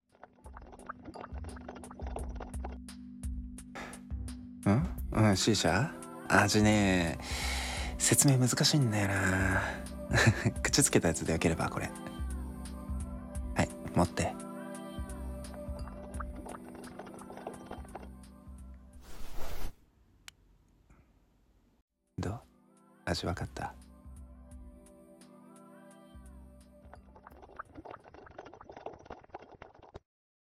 味、分かった？ 【スモーキーシナリオ 1人声劇】